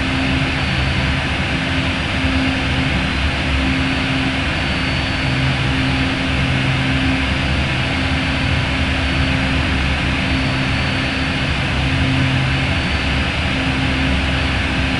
A320-family/Sounds/SASA/CFM56B/cockpit/cfm-idle.wav at 040feca1743ead2988bdfa6d4898d69c7c4cf4a7
cfm-idle.wav